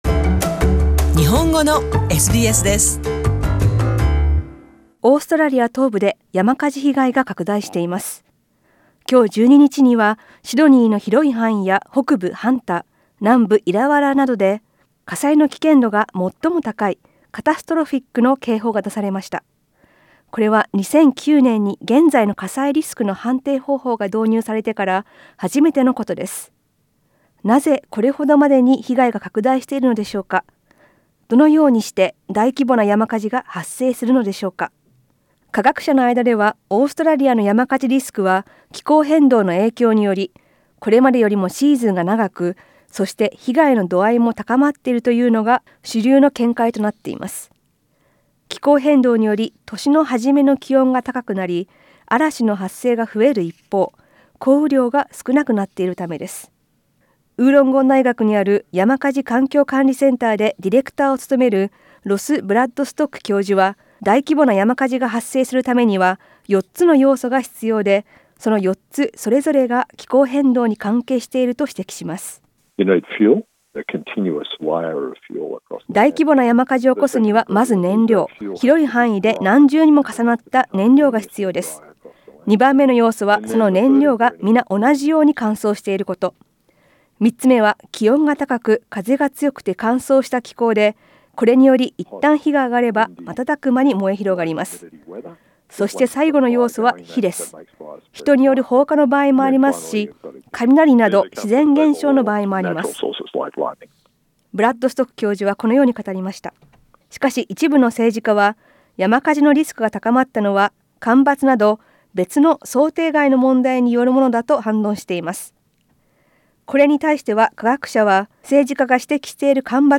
詳しくは写真をクリックして音声リポートをお聞き下さい。